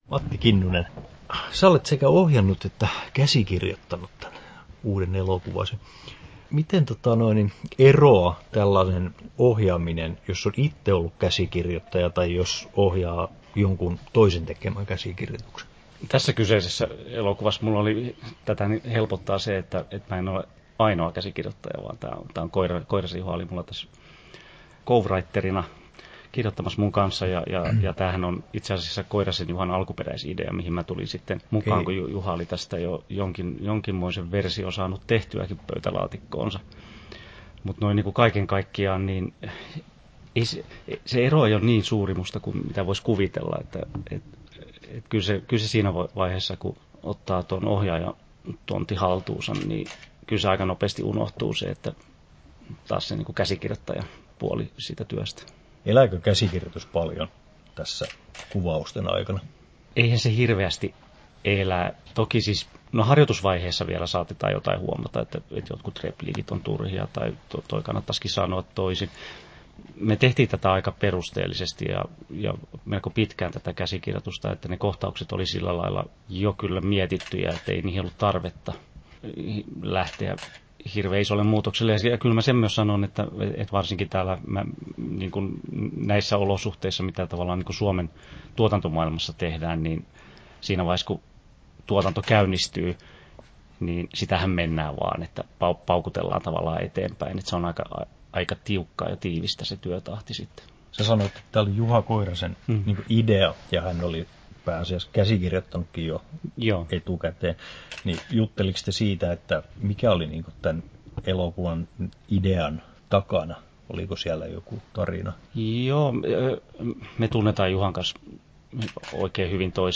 9'41" Tallennettu: 31.10.2018, Turku Toimittaja